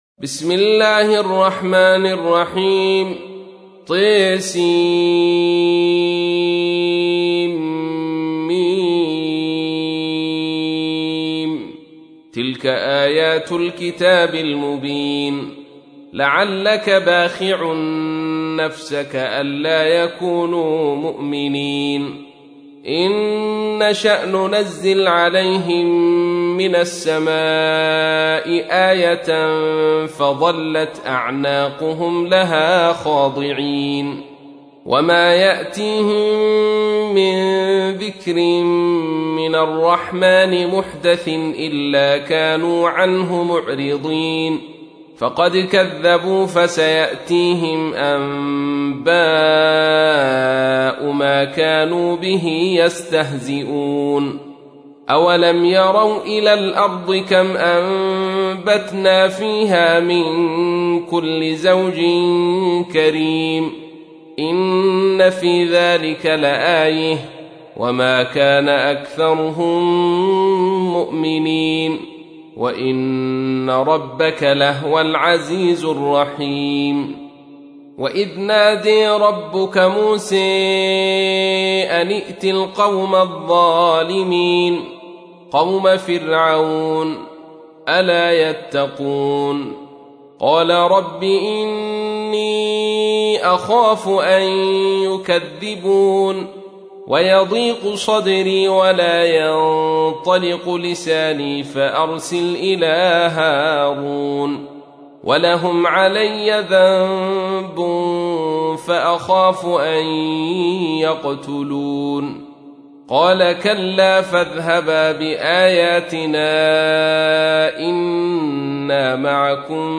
تحميل : 26. سورة الشعراء / القارئ عبد الرشيد صوفي / القرآن الكريم / موقع يا حسين